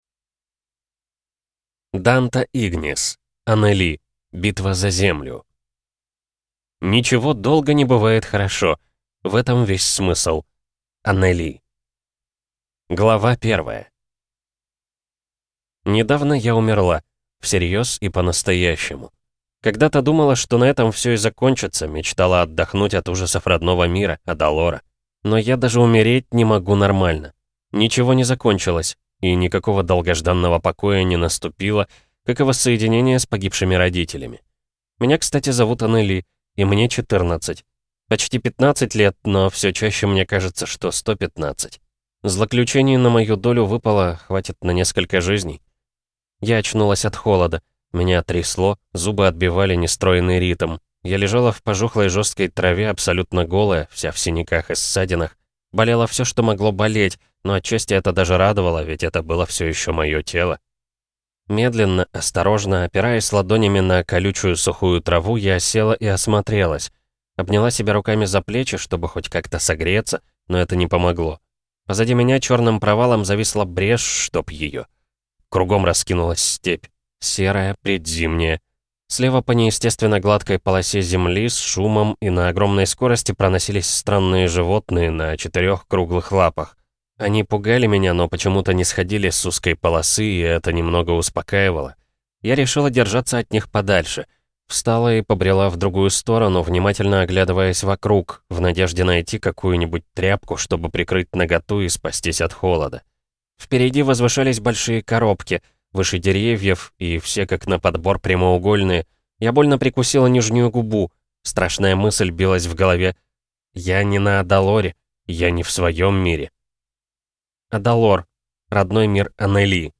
Аудиокнига Анели. Битва за Землю | Библиотека аудиокниг